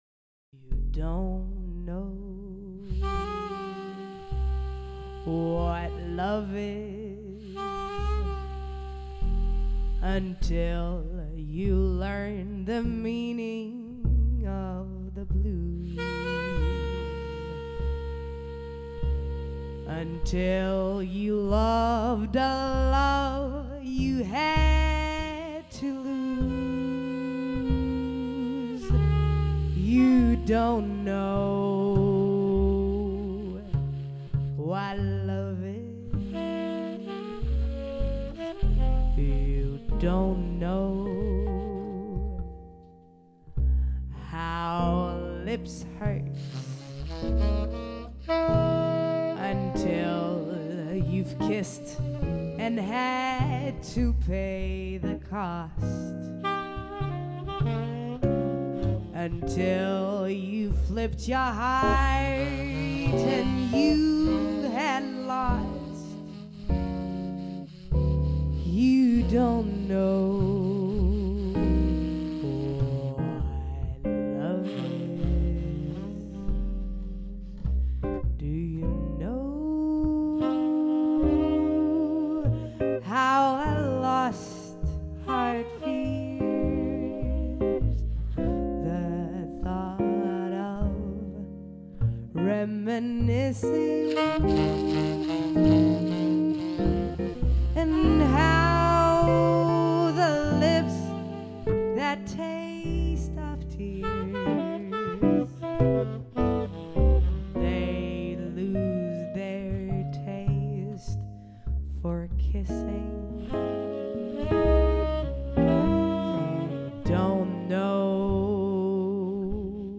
Jazz Vocal